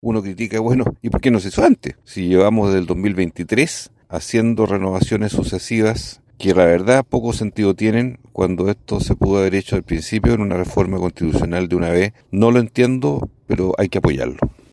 El senador del Partido Socialista, Juan Luis Castro, dijo no entender por qué se intenta ahora ingresar esta reforma constitucional, pero -aun así- comprometió su respaldo.